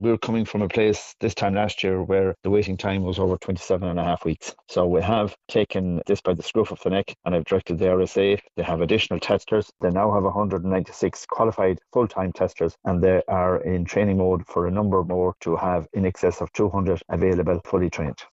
Junior Minister for Road Safety Seán Canney says this represents progress in reducing waiting times: